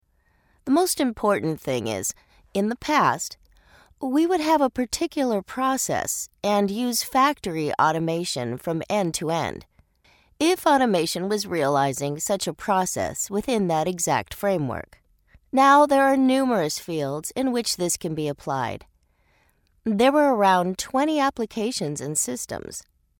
Voiceovers American English  female voice overs. Group A